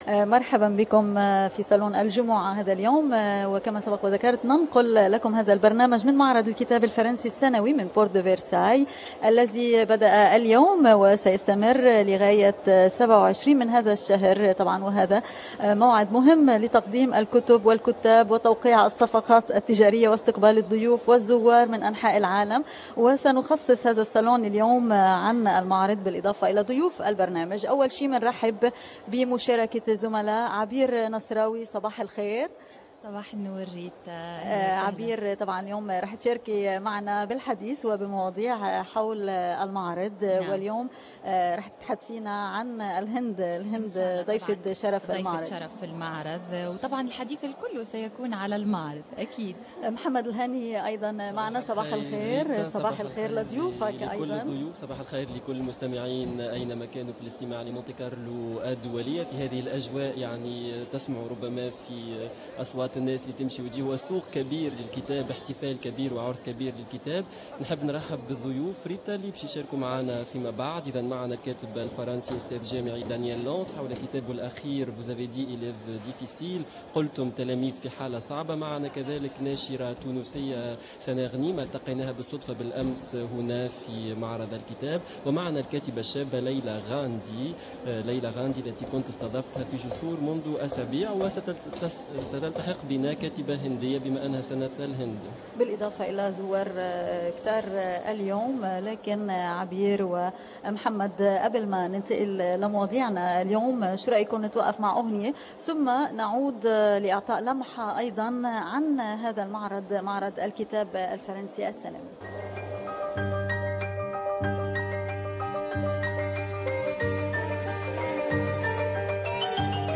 Au Salon du livre à Paris, Radio Monte-Carlo Doualiya , RFI International (bilingue français-arabe)
salon du livre.rm